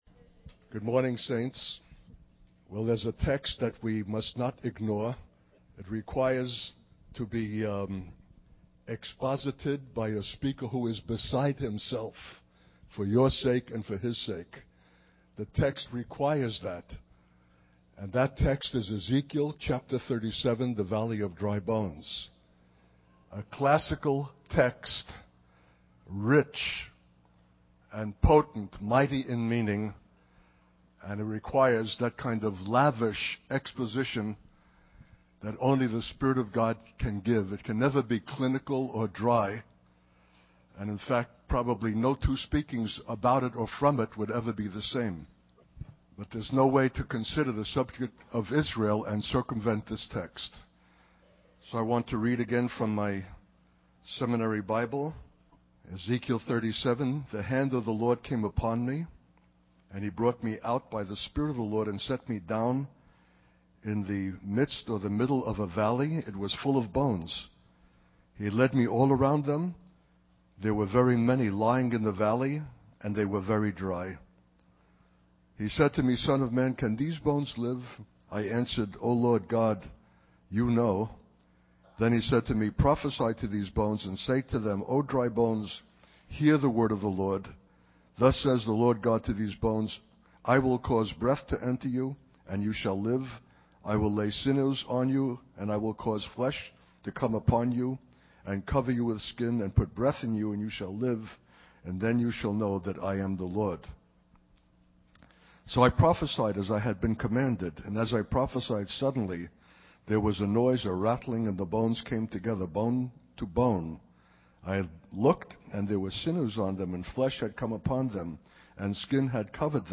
In this sermon, the speaker emphasizes the importance of understanding the predicament that Israel is facing and why the news is tightening for them. He explains that God is the author of this situation and it is leading to irrevocable disaster for Israel.